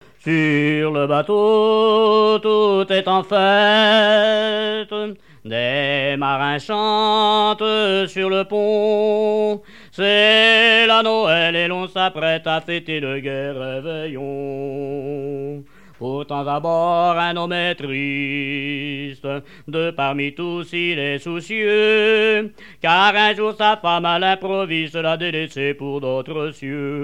Mémoires et Patrimoines vivants - RaddO est une base de données d'archives iconographiques et sonores.
témoignages et chansons maritimes
Pièce musicale inédite